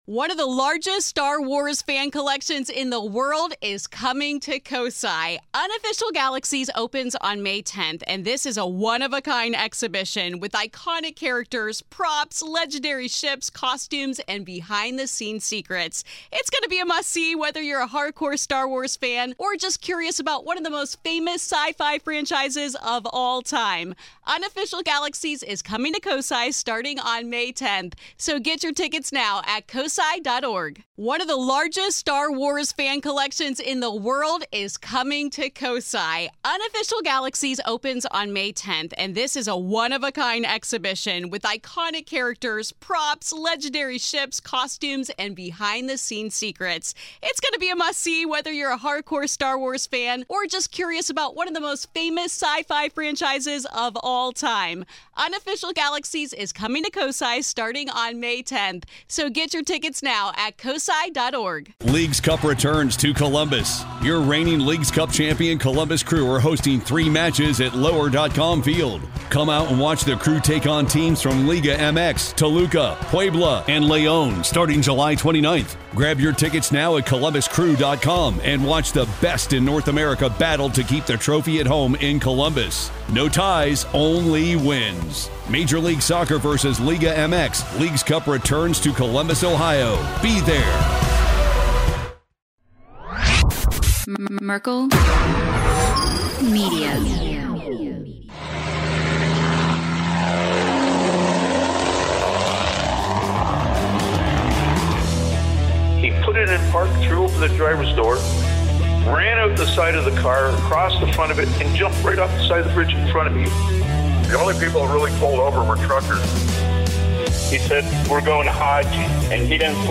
With personal stories, humor, and a shared love for concerts, the conversation weaves through life’s ups and downs, ending with lighthearted talk about music and future podcast ideas.